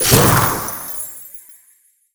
ice_blast_projectile_spell_02.wav